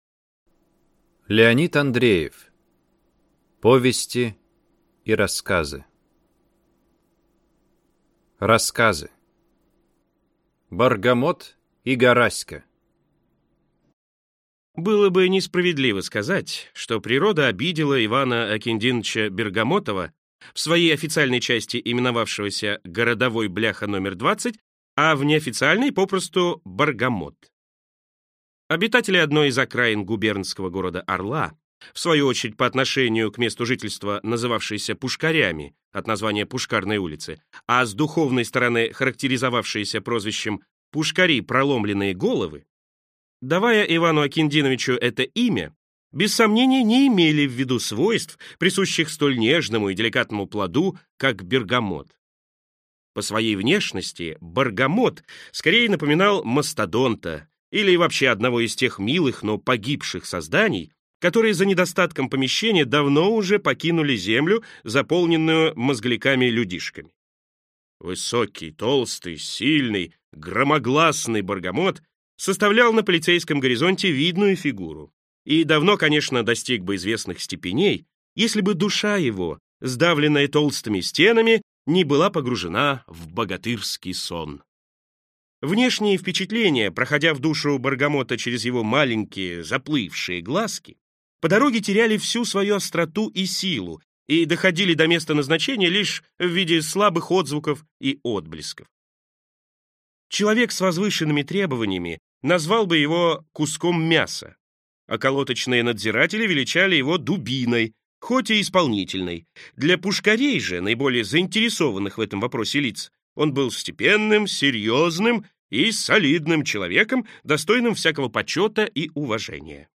Аудиокнига Повести и рассказы | Библиотека аудиокниг